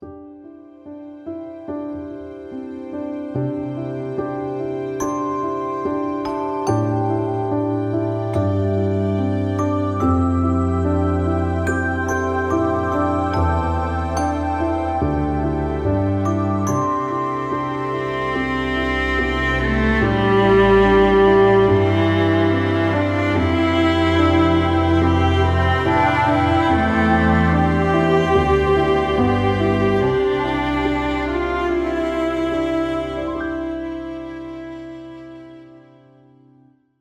Meet Me In The Forest (Love Theme)